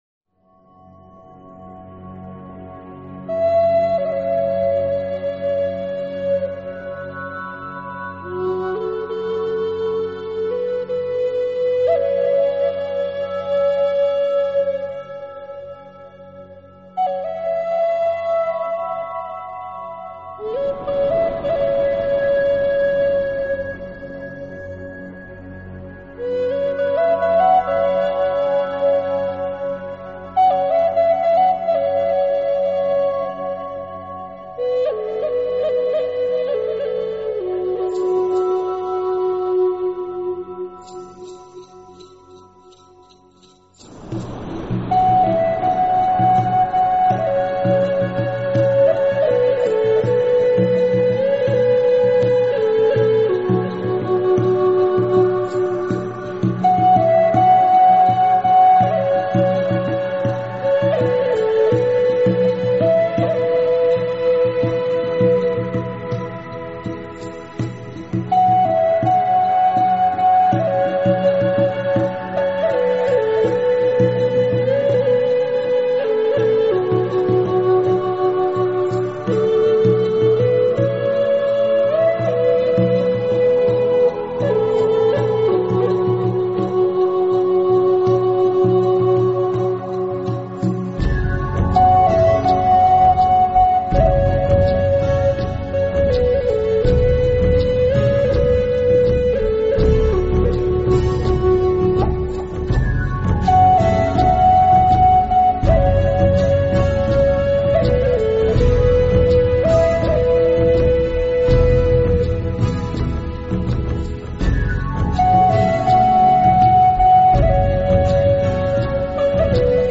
辑来说有很大的不同，这个系列充满了异国风情和朴素原始的旋
的乐器十分丰富，除了大量的民族乐器以外，最突出的要数笛声